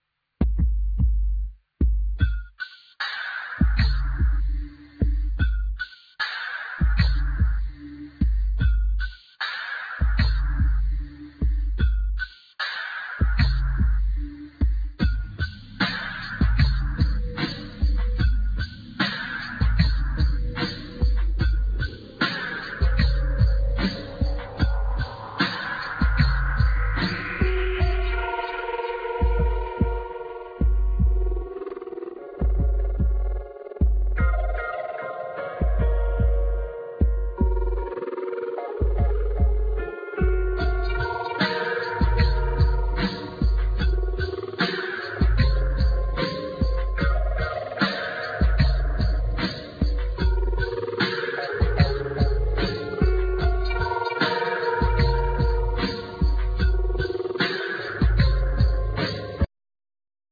Acoutic & electric piano,Vocals
Keyboards,Electronics,Loops,Electric piano
Pedal steel guitar
Trumpet
Treated guitar
Percussions
Flute,Clarinet
Treated Kantele
Midi guitar
Double bass